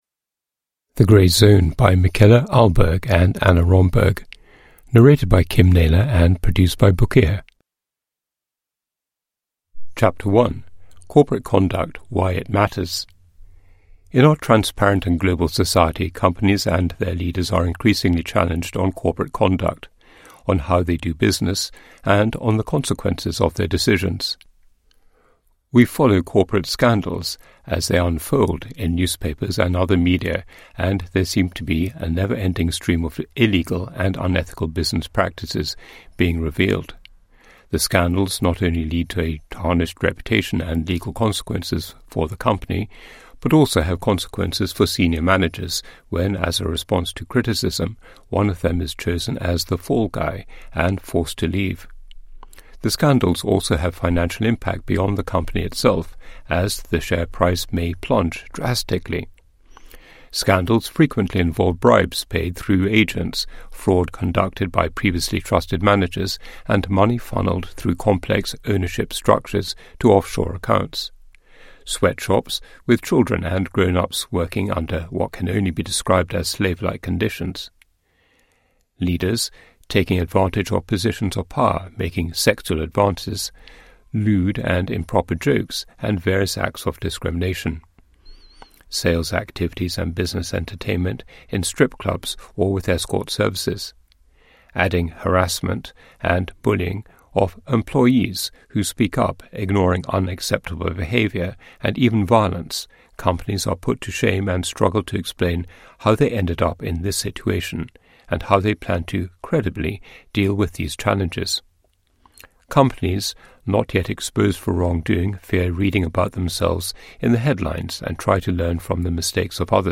The grey zone : a practical guide to corporate conduct, compliance and business ethics – Ljudbok
• Ljudbok